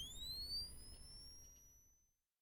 Cannon [Charging].aiff